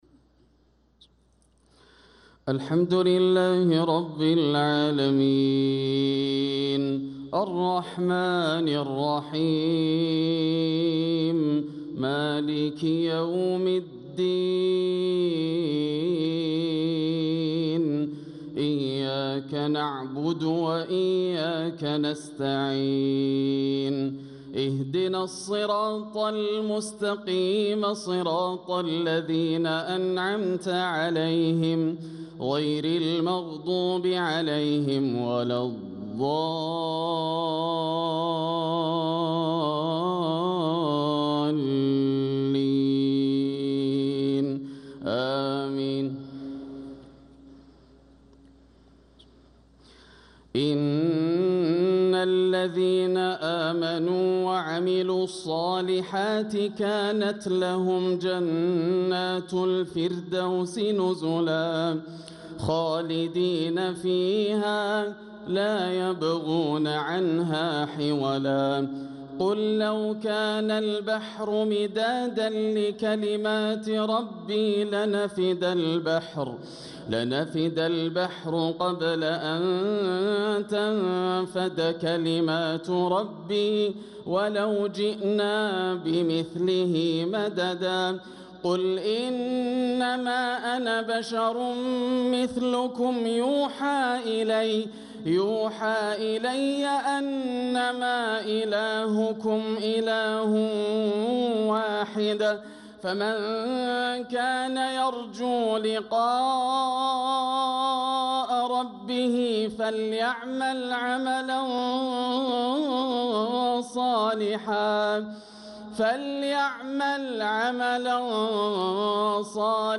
صلاة المغرب للقارئ ياسر الدوسري 15 رجب 1446 هـ
تِلَاوَات الْحَرَمَيْن .